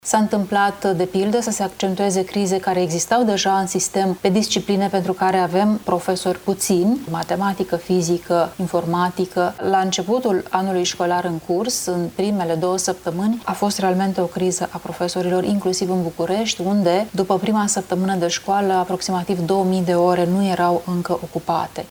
Luciana Antoci, consilier de stat la Cancelaria premierului Bolojan,  a declarat într-un interviu la TVR Iași că „Ministerul Educației ia în calcul revenirea asupra acestei decizii”.